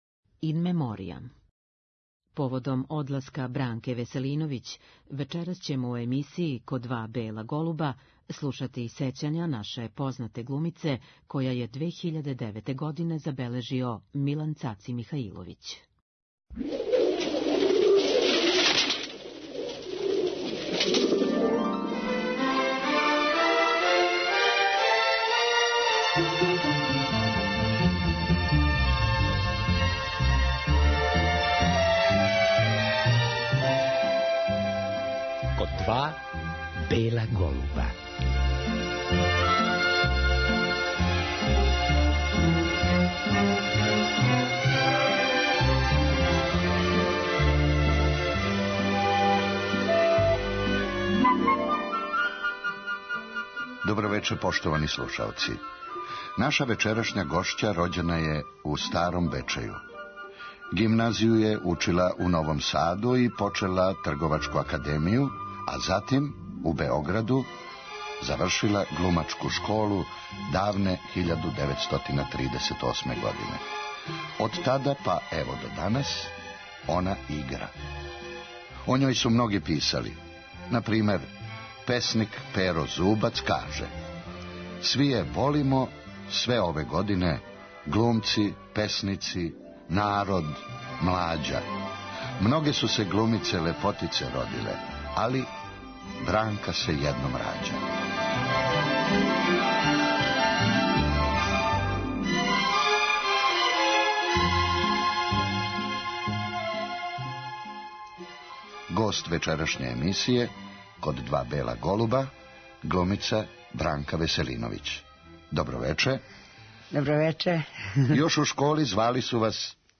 Поводом одласка Бранке Веселиновић у вечерашњој емисији слушаћемо сећања наше познате глумице забележена 2009. године.